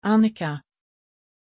Aussprache von Anikka
pronunciation-anikka-sv.mp3